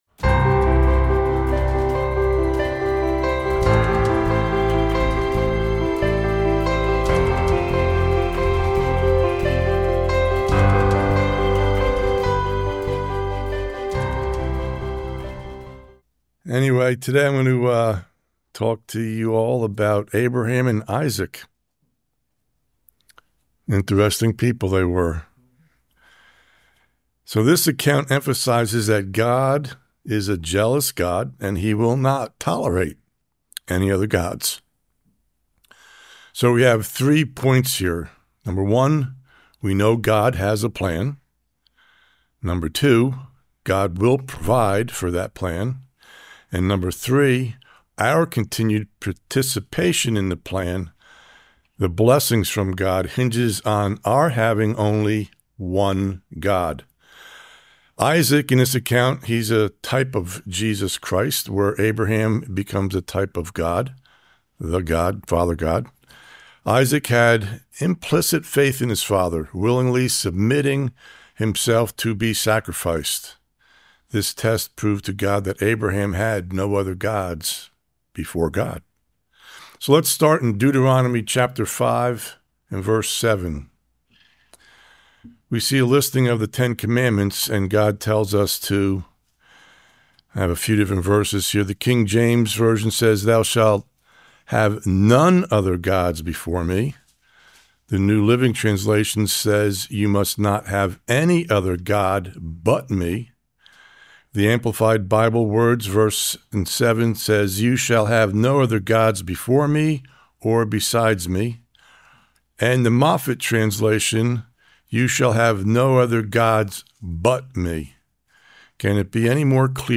Sermons
Given in Charlotte, NC Hickory, NC Columbia, SC